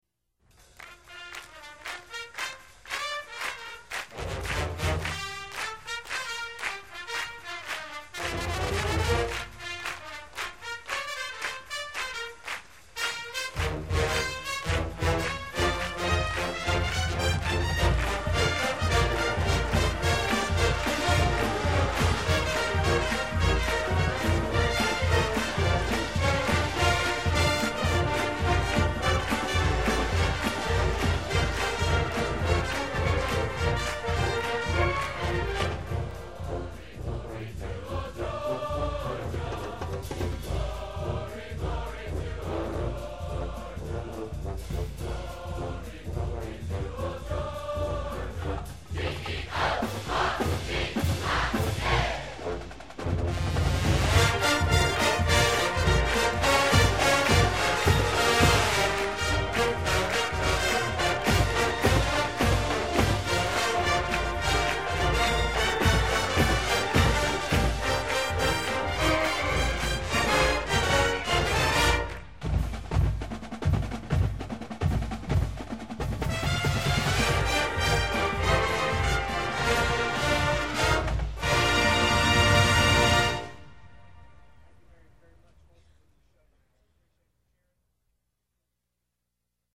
dawgsglorydixieland.mp3